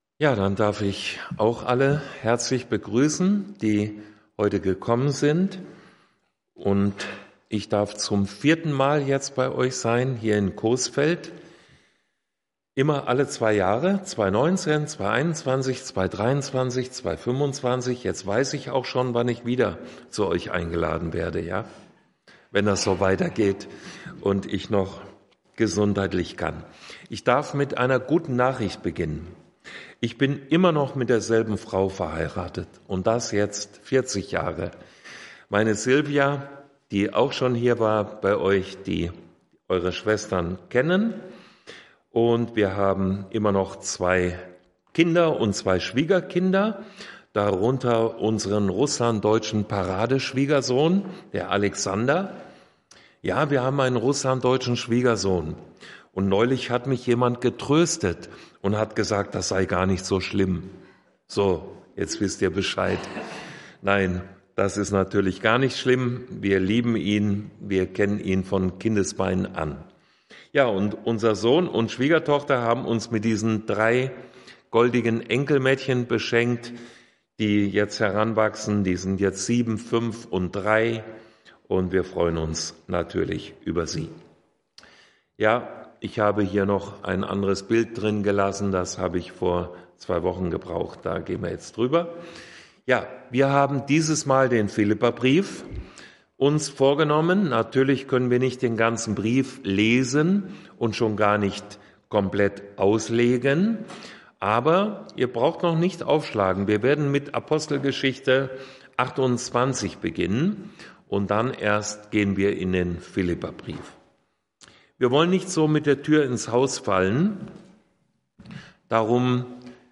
Vortragsreihe